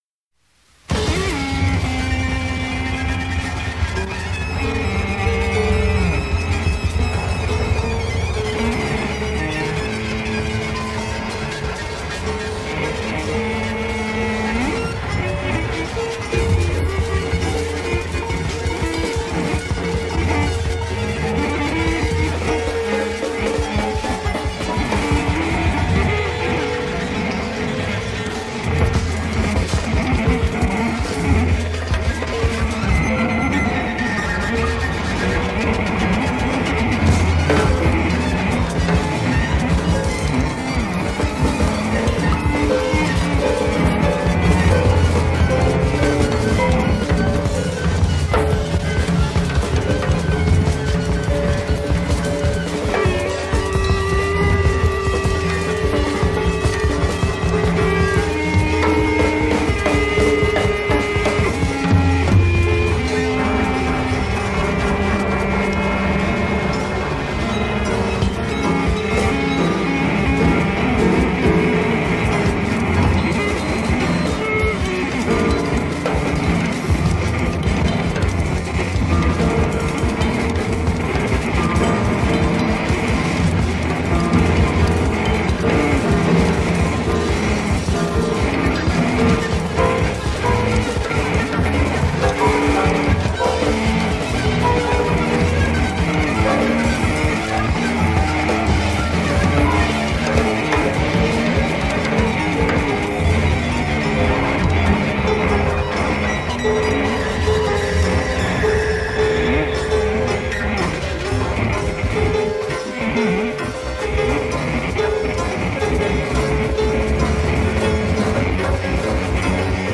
recorded live on September 6, 1977